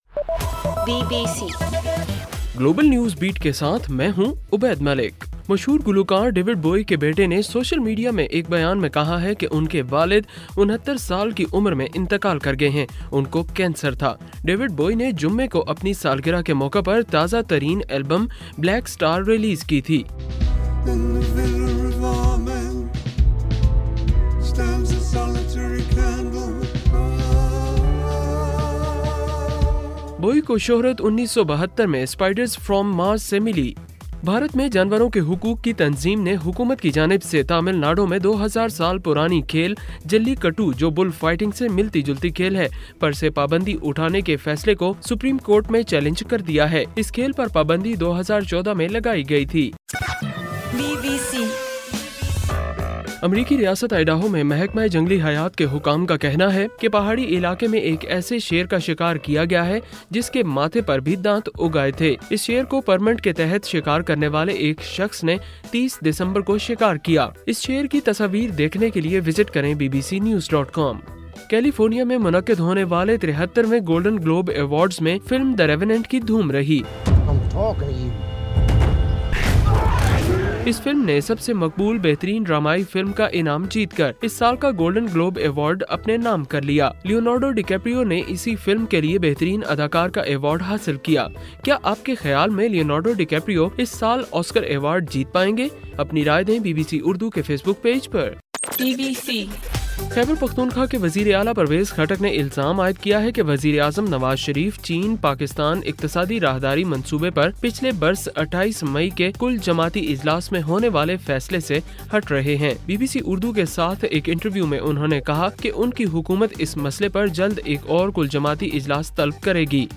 جنوری 11: رات 8 بجے کا گلوبل نیوز بیٹ بُلیٹن